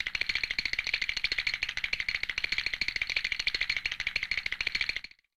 Teeth Chatter
Category: Sound FX   Right: Commercial